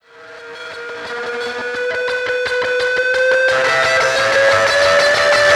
Track 02 - Guitar Lead 06.wav